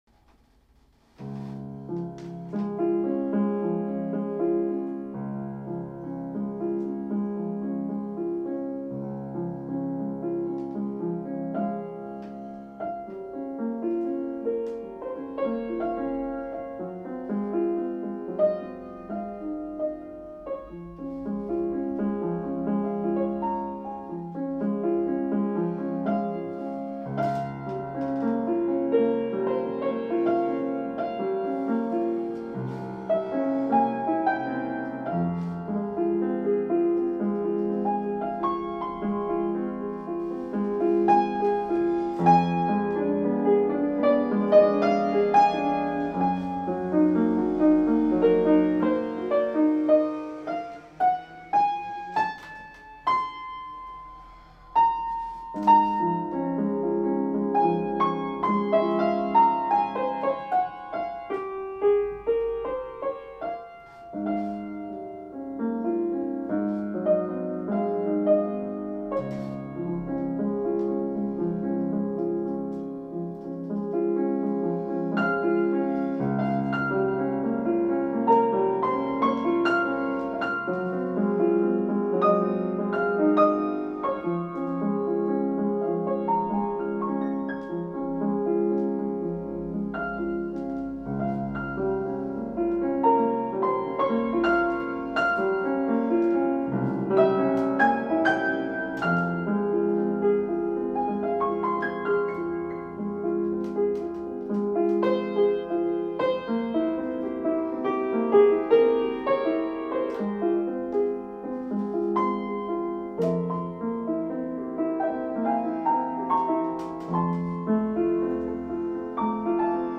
consolation de Liszt (la troisième, la plus connue). Pas facile quoique lent...